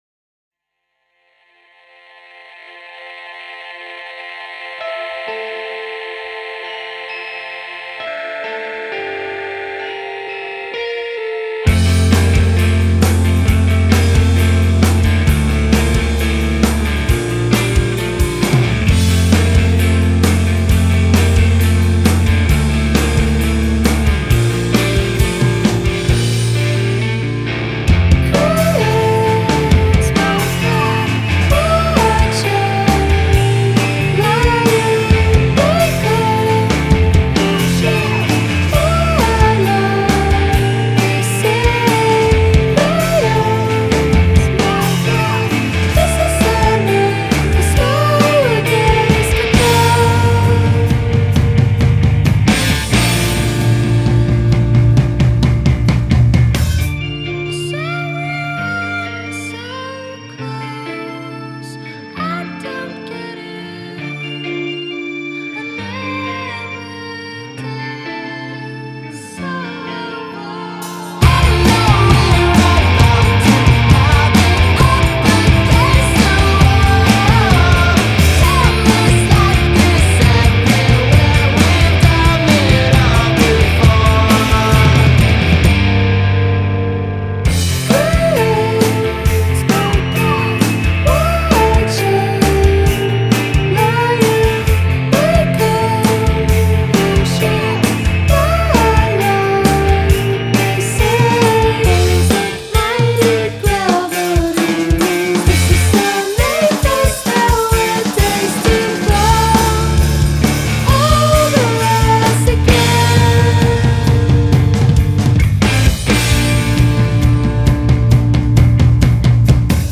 Way more pop to be sure, though still rooted in hardcore.